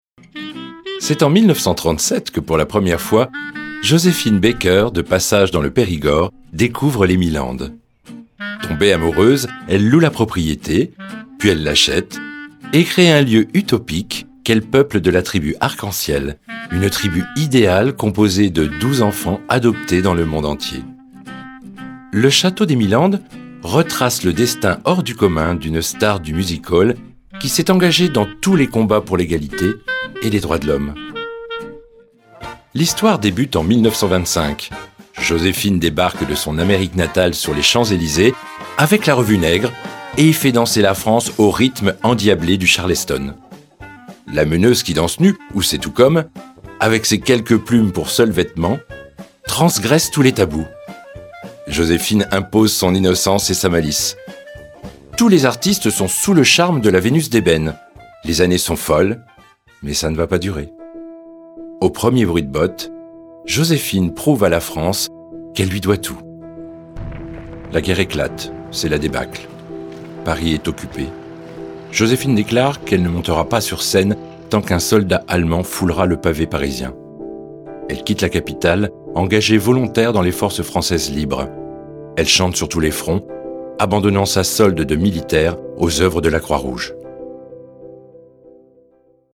45 - 65 ans - Basse